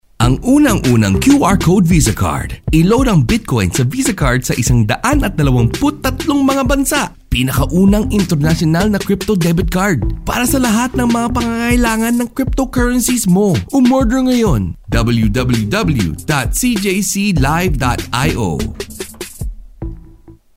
菲律宾语中年沉稳 、娓娓道来 、积极向上 、男专题片 、宣传片 、纪录片 、广告 、课件PPT 、工程介绍 、绘本故事 、动漫动画游戏影视 、350元/百单词男菲律宾01 菲律宾语男声 广告 沉稳|娓娓道来|积极向上
男菲律宾01 菲律宾口音英语男声 干音1 大气浑厚磁性|沉稳|娓娓道来